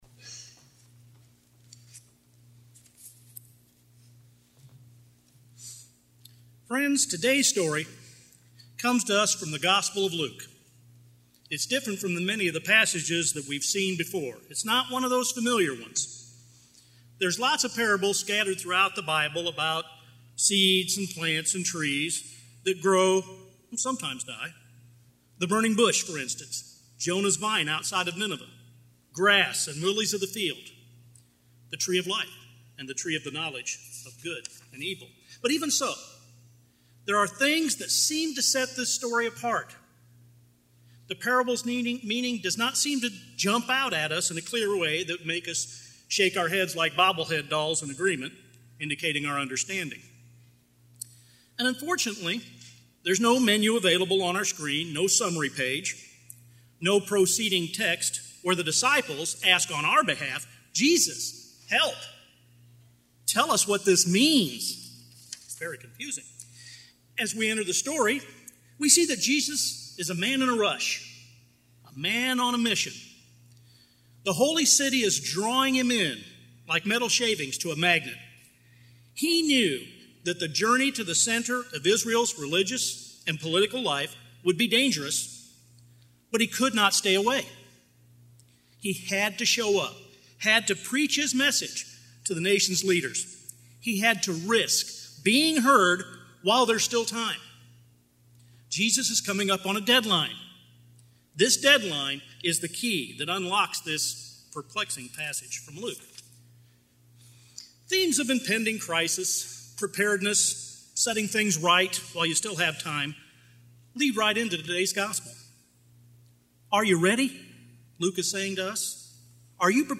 Sermons 2010 Central Presbyterian Church Russellville Arkansas